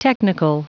Prononciation du mot technical en anglais (fichier audio)